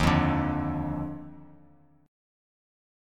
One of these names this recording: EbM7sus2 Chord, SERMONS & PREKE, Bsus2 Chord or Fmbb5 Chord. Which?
EbM7sus2 Chord